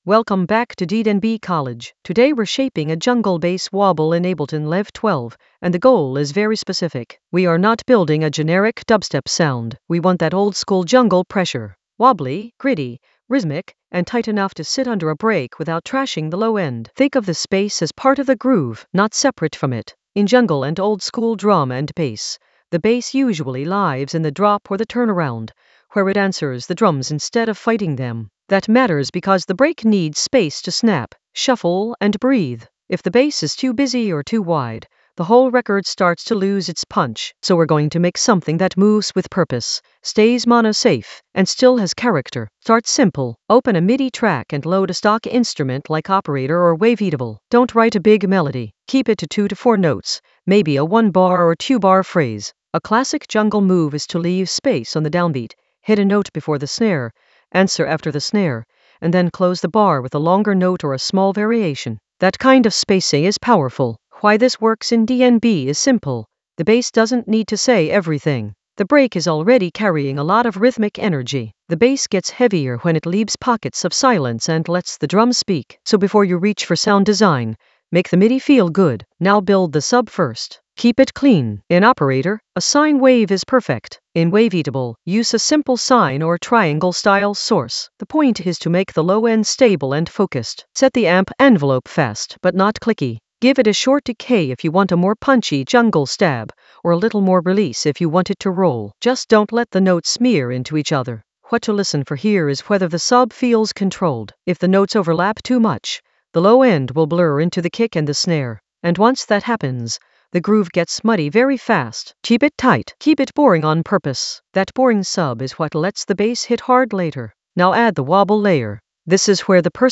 An AI-generated beginner Ableton lesson focused on Shape a jungle bass wobble in Ableton Live 12 for jungle oldskool DnB vibes in the Groove area of drum and bass production.
Narrated lesson audio
The voice track includes the tutorial plus extra teacher commentary.